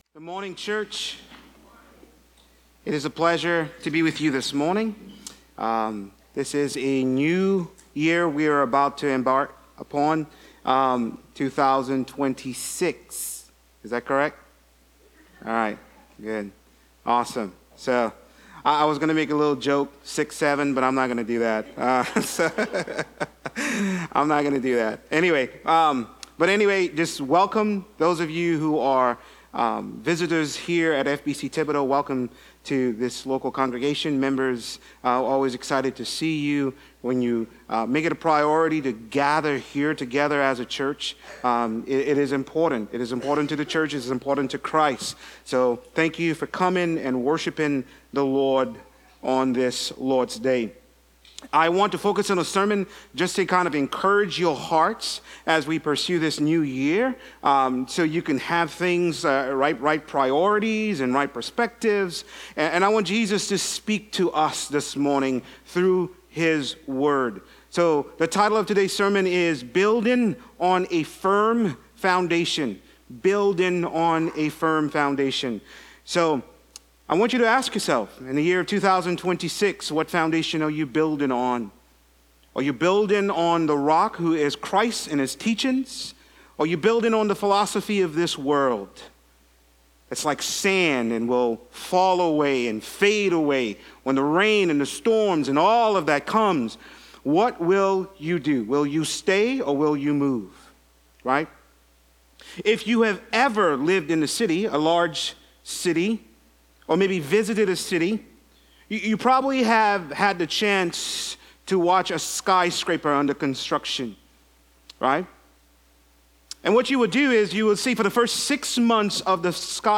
These are sermons not associated with any particular sermon series.